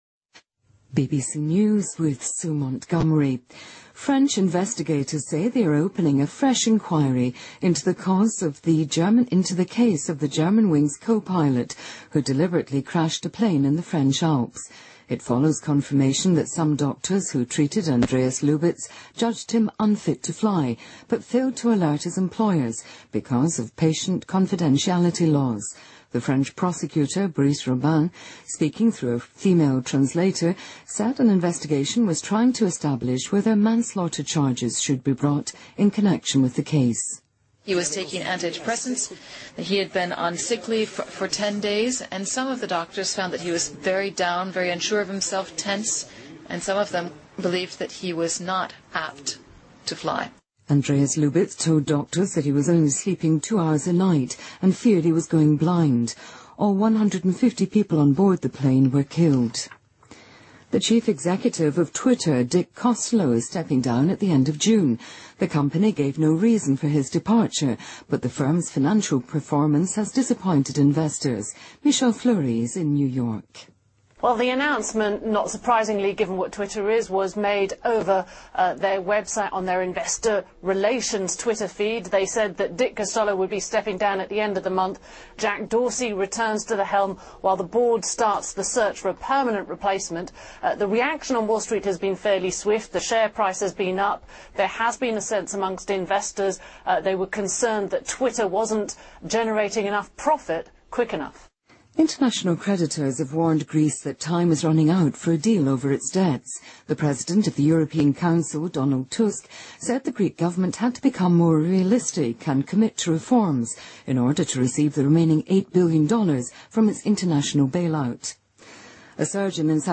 BBC news,推特总裁迪克将于六月底辞职